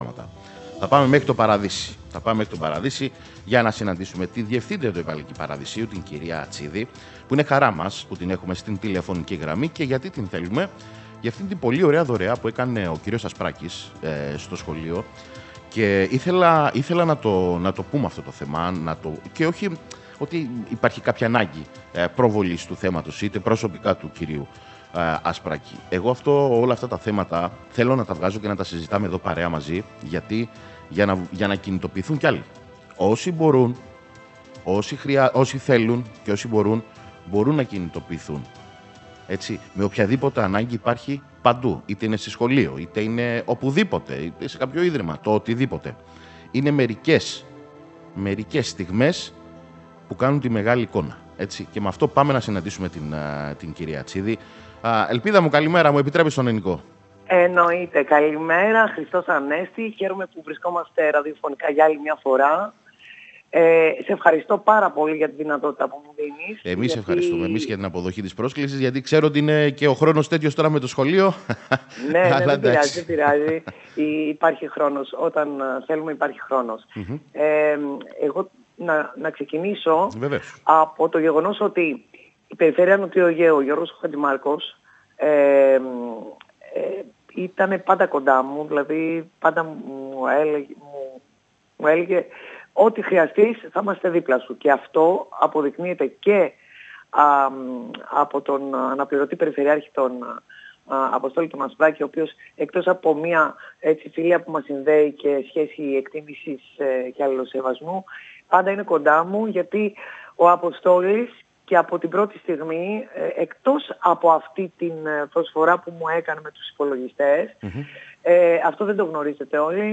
Στην εκπομπή «Πρώτη Καλημέρα»